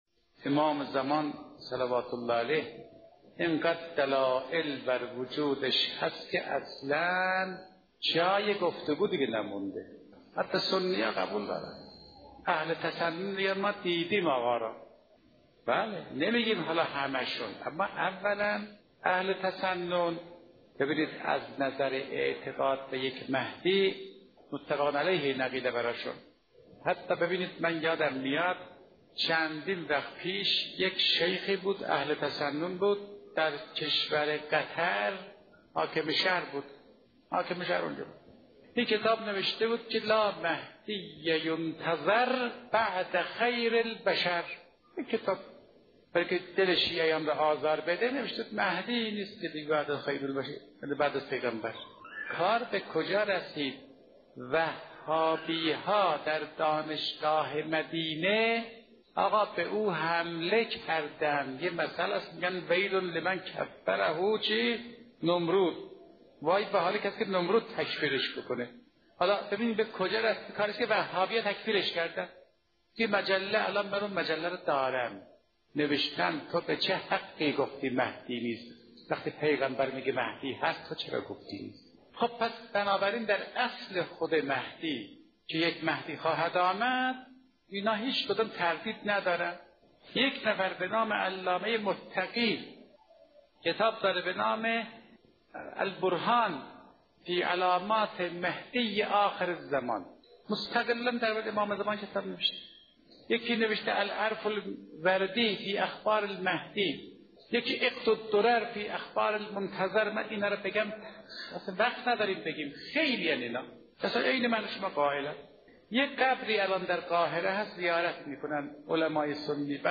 کلام استاد بنام اخلاق مرحوم حجت‌الاسلام والمسلمین فاطمی‌نیا با عنوان «اعتقاد اهل سنت به امام زمان (عج)» تقدیم مخاطبان گرامی الکوثر می‌شود.